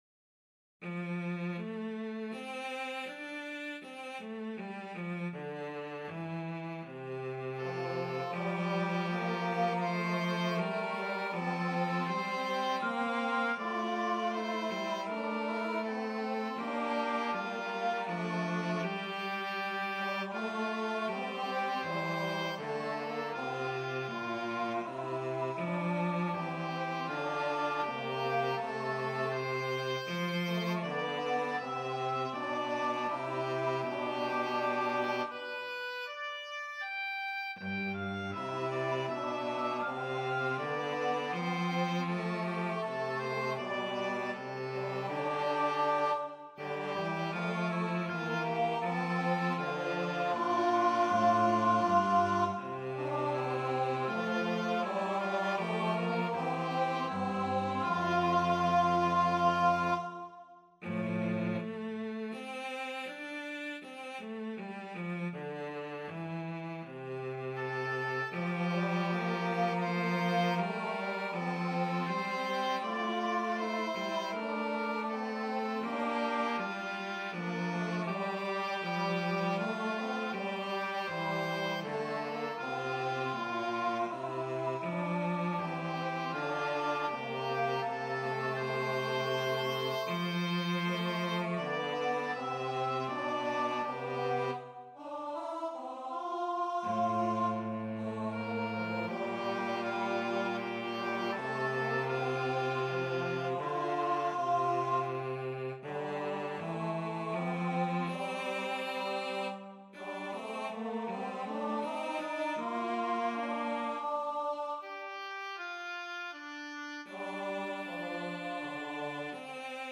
for Voices and Instruments
Tenor; Oboe; Cello